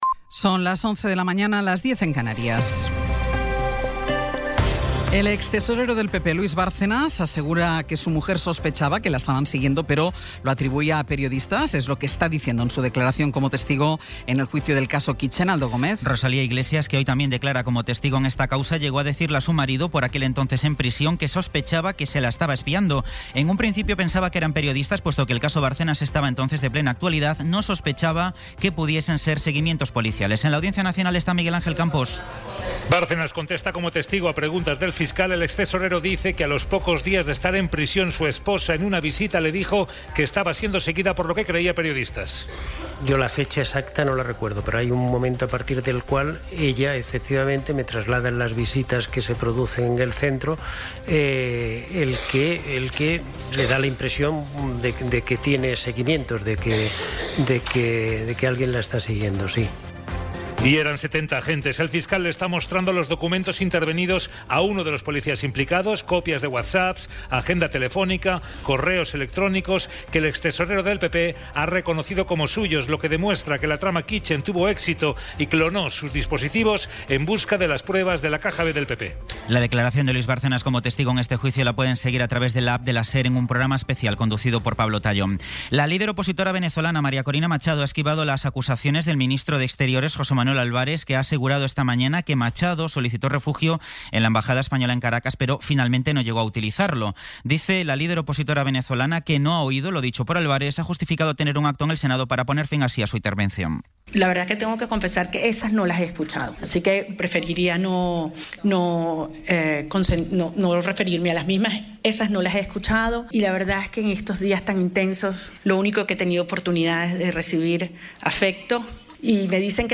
Resumen informativo con las noticias más destacadas del 20 de abril de 2026 a las once de la mañana.